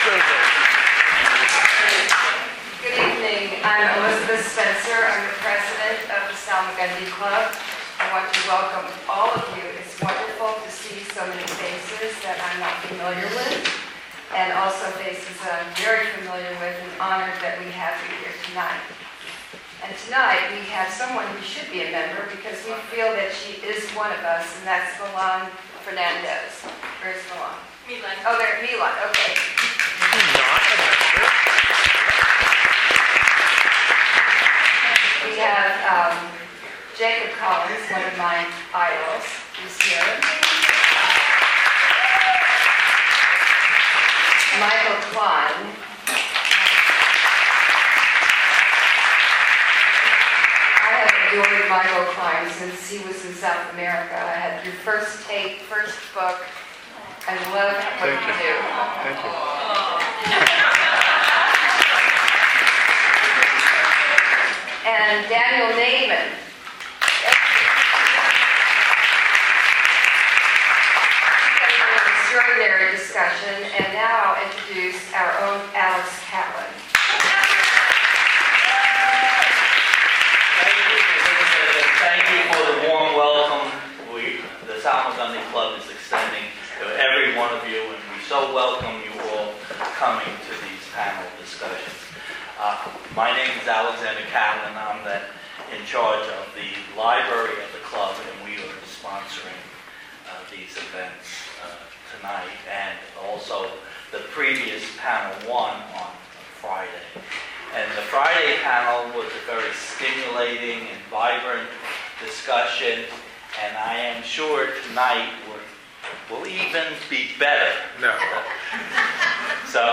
Panel Discussion: The Responsibility of the Artist in the 21st Century